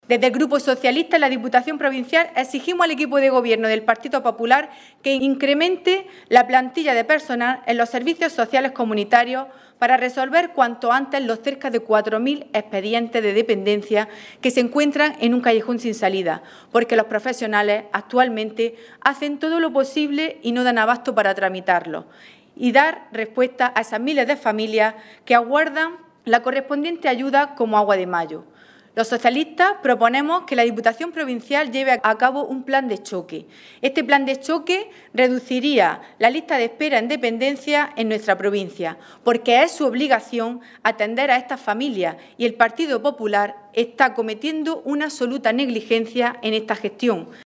hoy en rueda de prensa